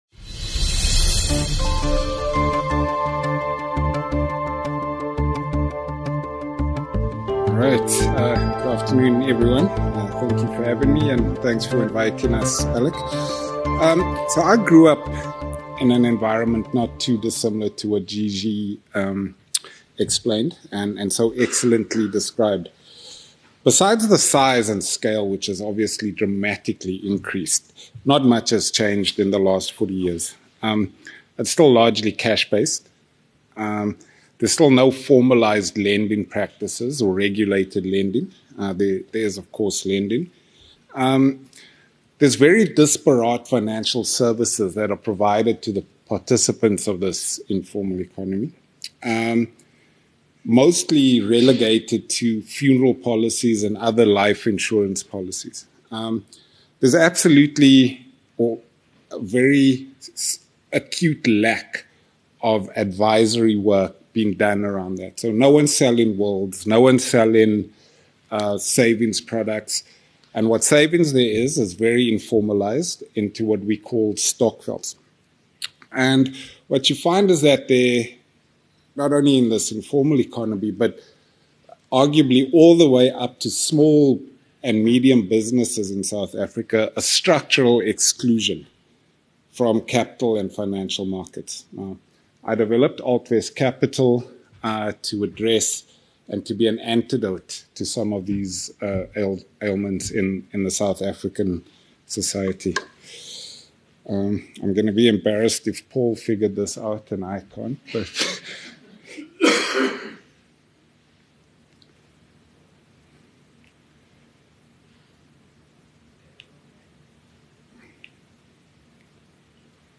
At the BizNews Conference in London